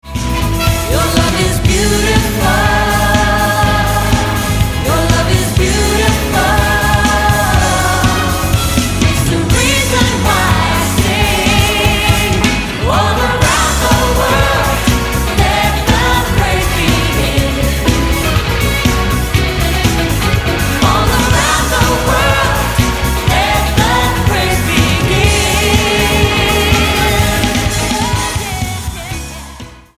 STYLE: Pop
complete with massed percussion solos and '70s disco flava